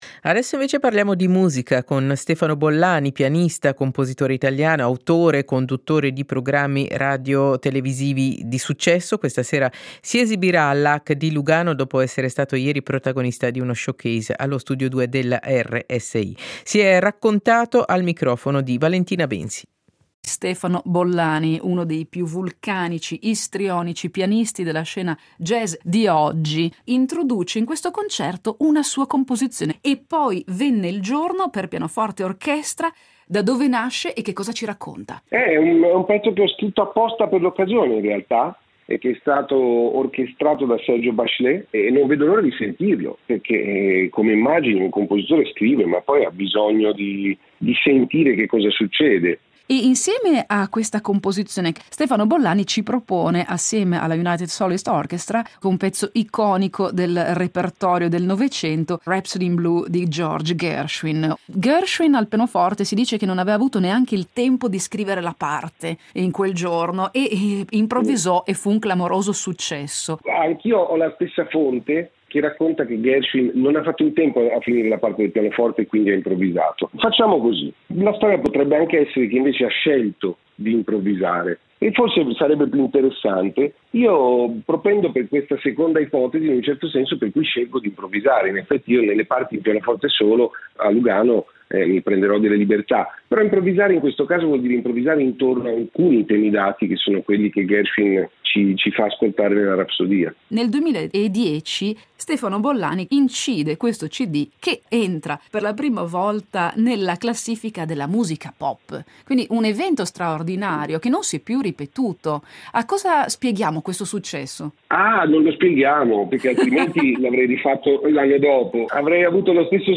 SEIDISERA del 06.02.2025: Musica: l'intervista a Stefano Bollani